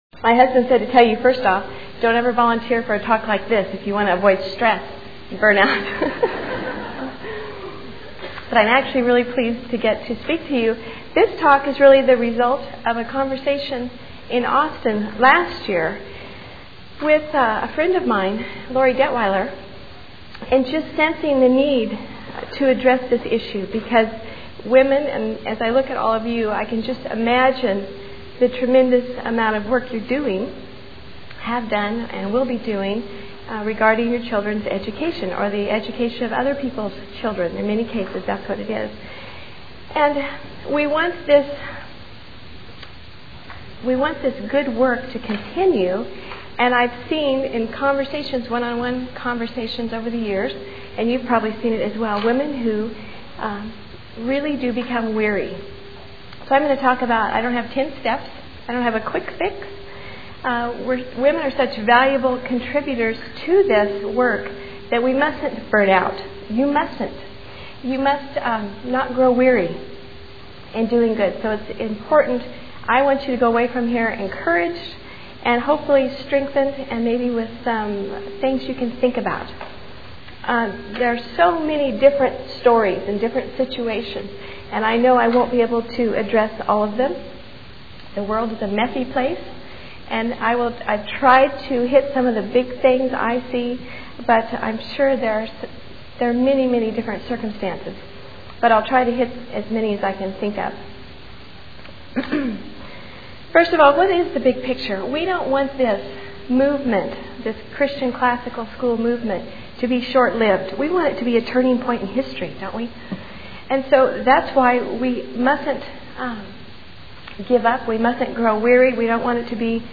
2000 Foundations Talk | 0:47:43 | Teacher & Classroom
The Association of Classical & Christian Schools presents Repairing the Ruins, the ACCS annual conference, copyright ACCS.